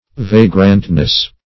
Vagrantness \Va"grant*ness\